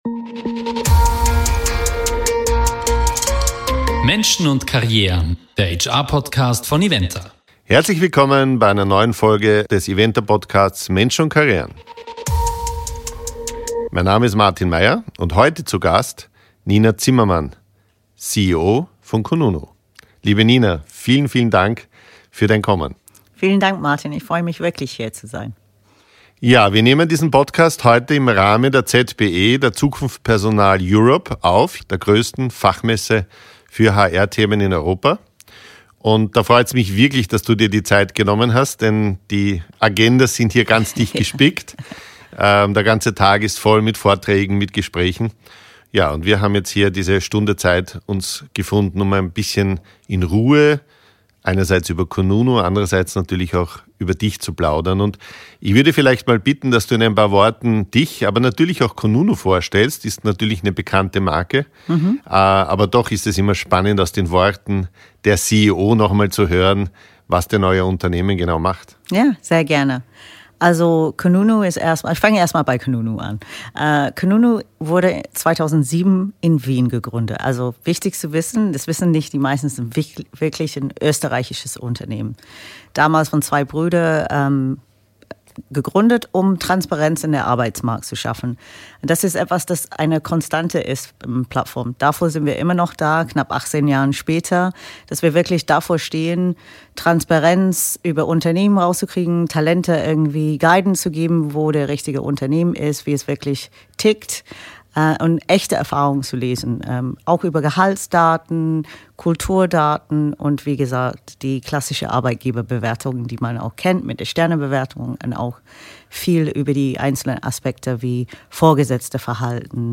Es geht um die Rolle von Unternehmenskultur, Feedback und Employer Branding für den Erfolg von Unternehmen. Ergänzt wird die Folge durch Stimmen von der HR-Messe ZPE in Köln, die Einblicke in aktuelle Themen wie Vertrauen, Leadership und KI geben.